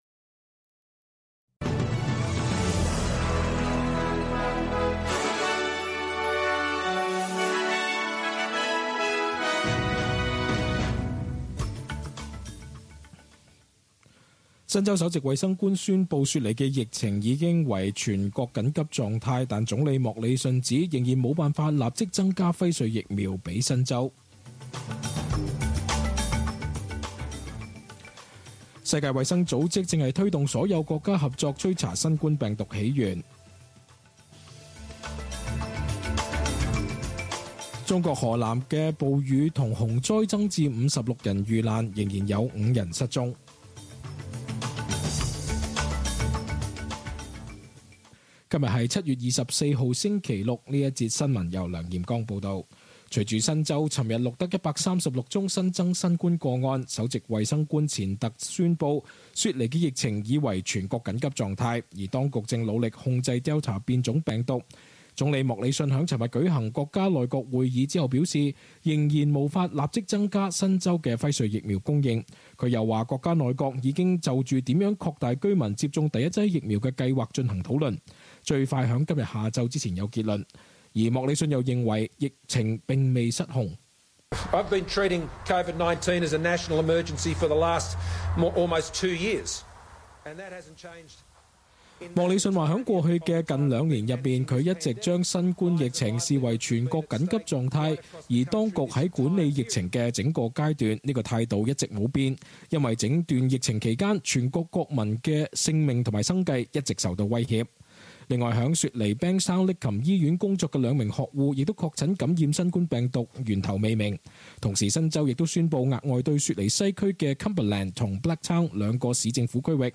cantonese_news_2407.mp3